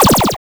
blaster.wav